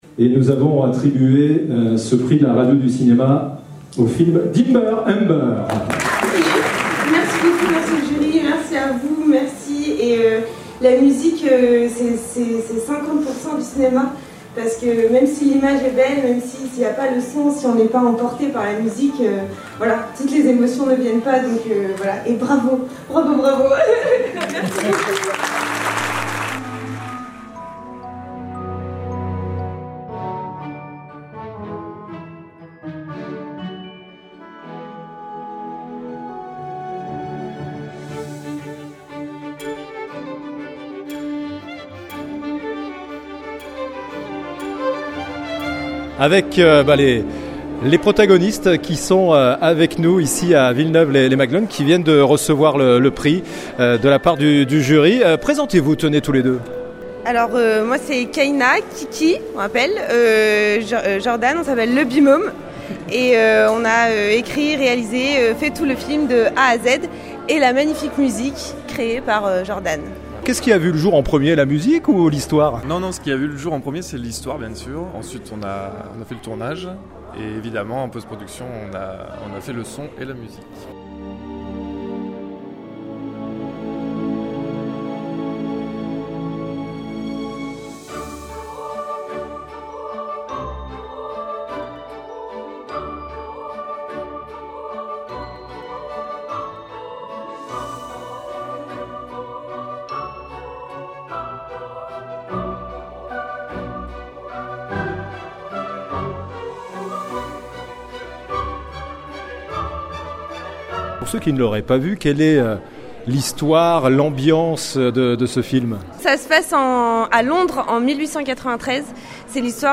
(extraits dans l'interview).